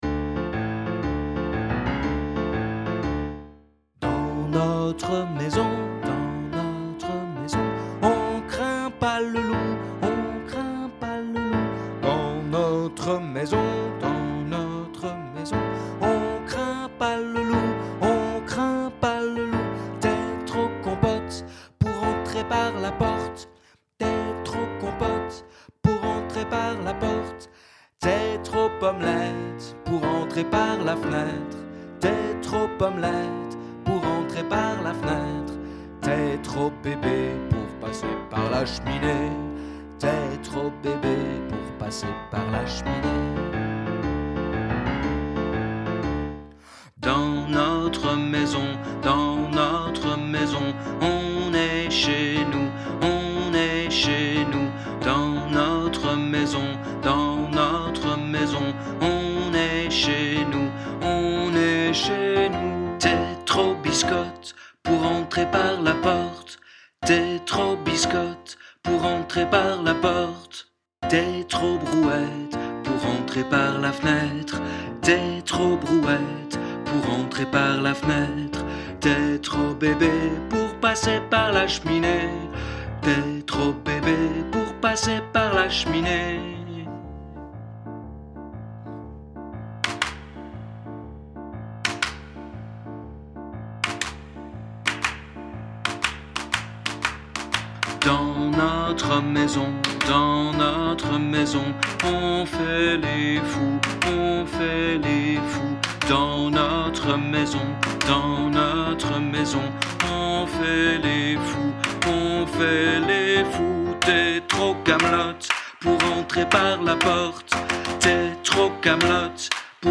Mélodie voix 2
comptine_voix2.mp3